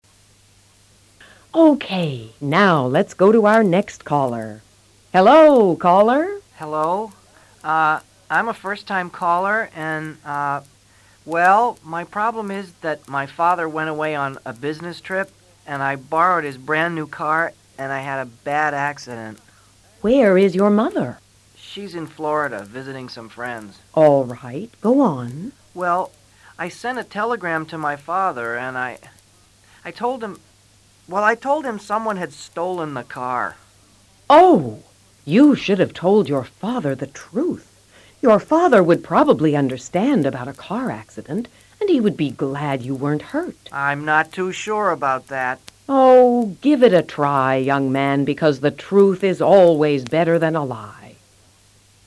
Escucha con atención al segundo oyente y trata de repetir la conversación luego.
Second caller